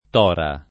t0ra] top. f. (Tosc.)